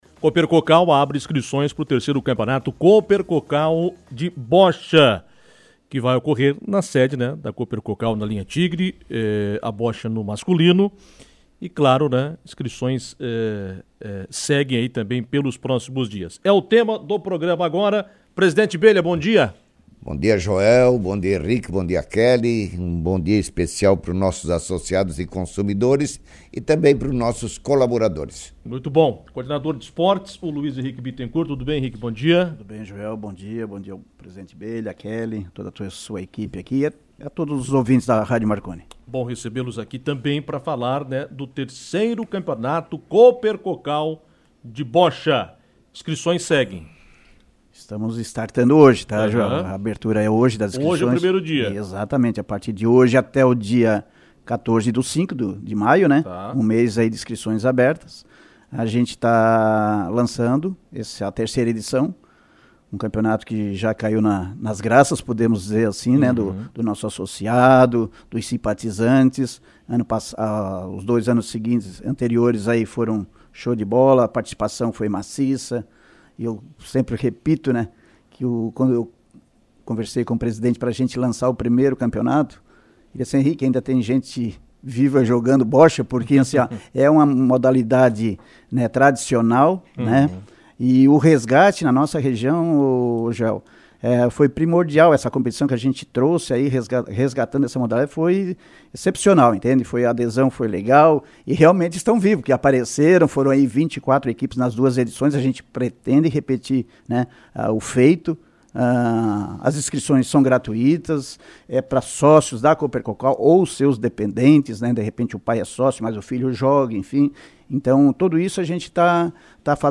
participaram de entrevista no programa Comando Marconi